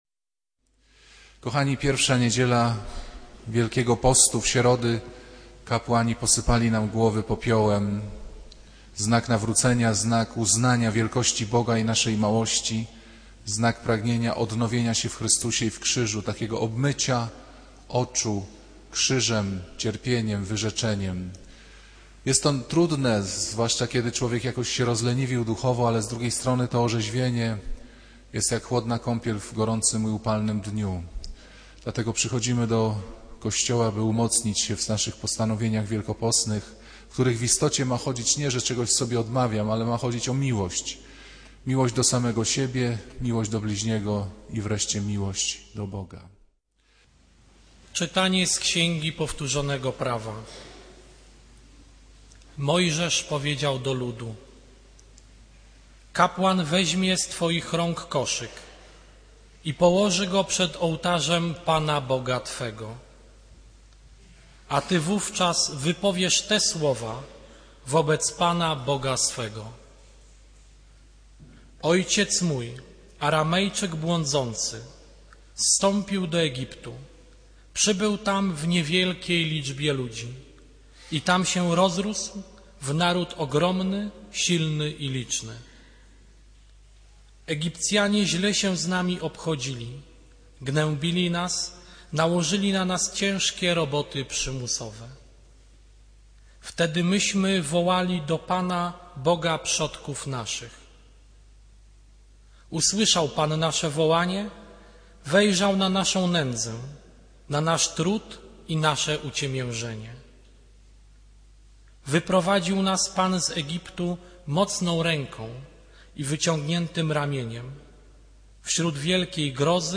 Kazanie z 19 lipca 2009r.